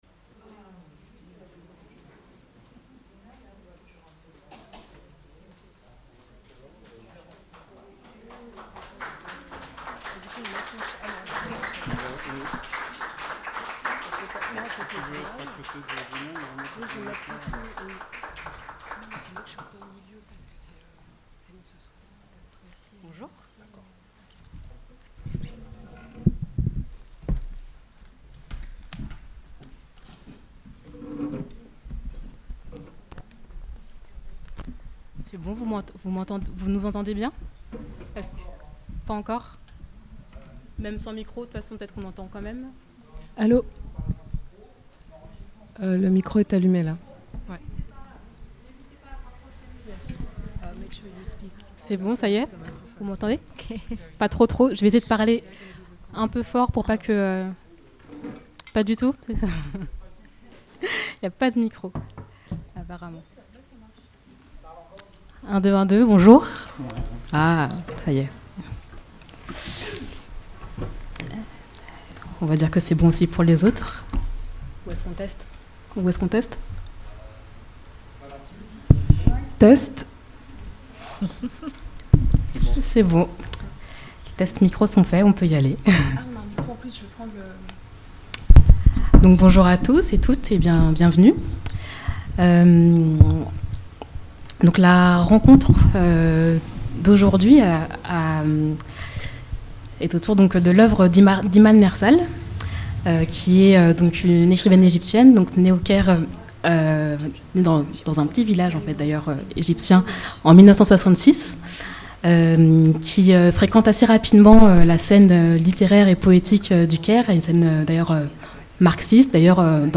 L’intégralité de la rencontre en écoute [1h15] :